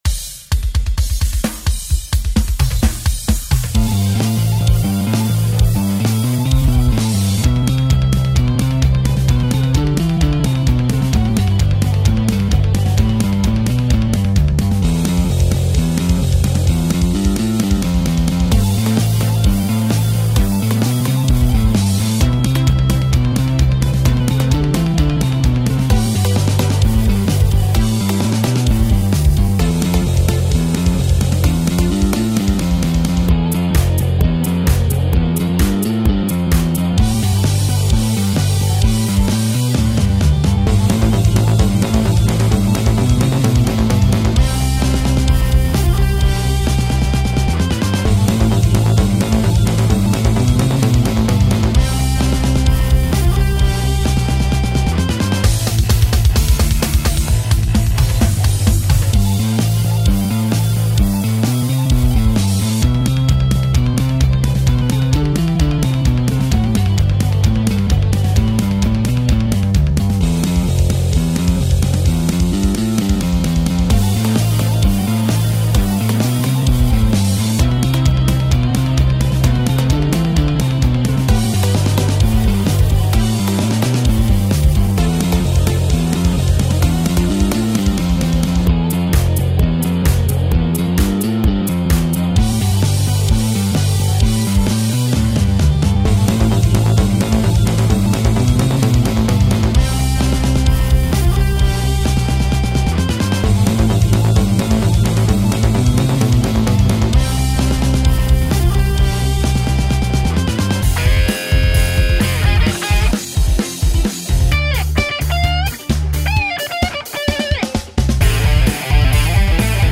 Home > Music > Rock > Running > Chasing > Restless